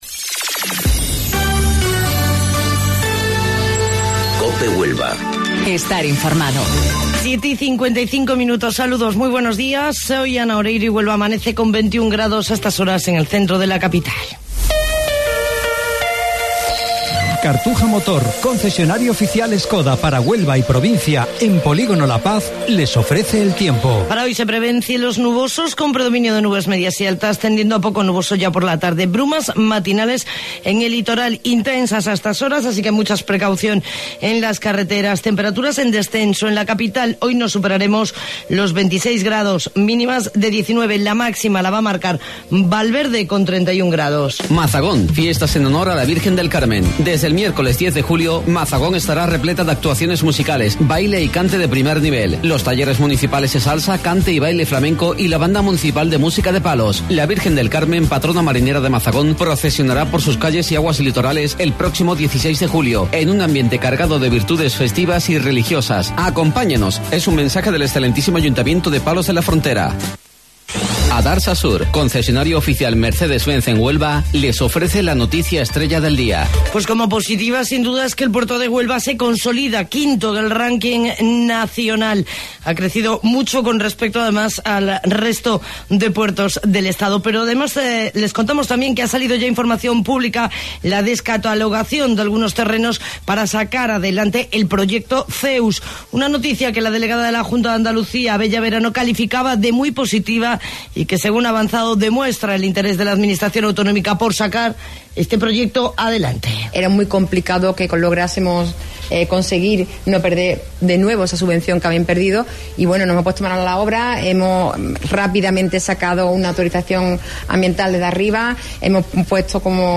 AUDIO: Informativo Local 07:55 del 12 de Julio